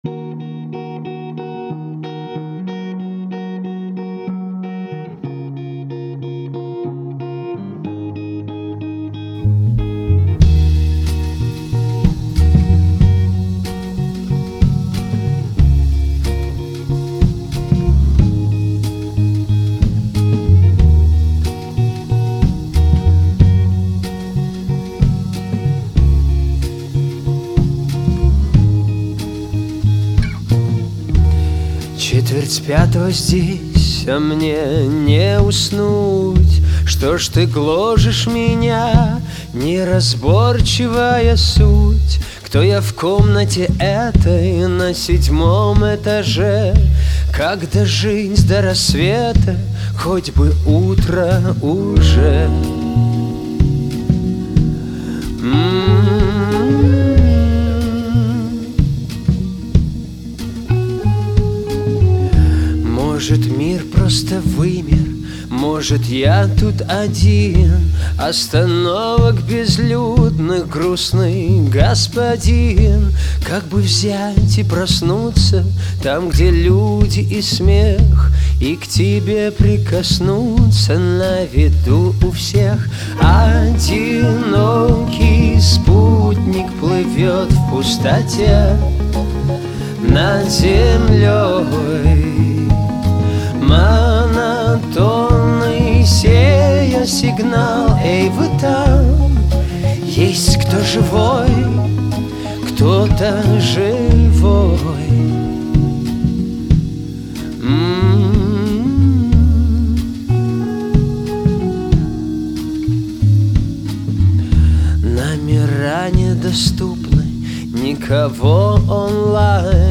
Категория: ROCK